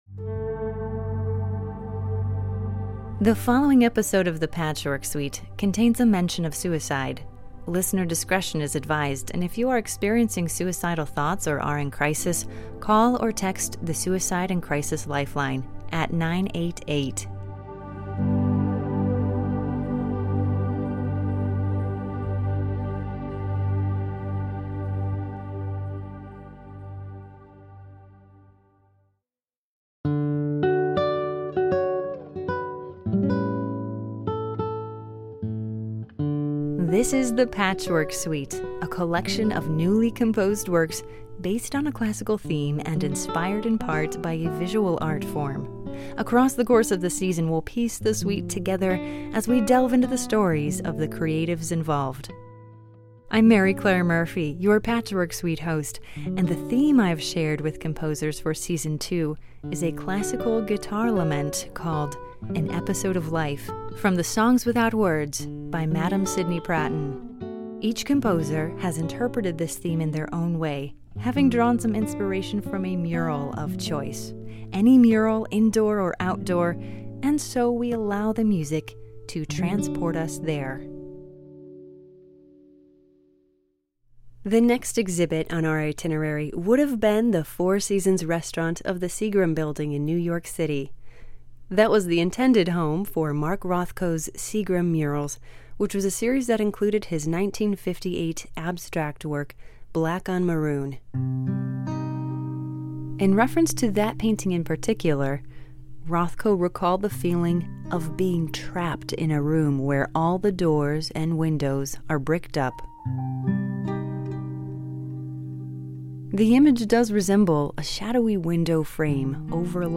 sweeping episodes for cello and piano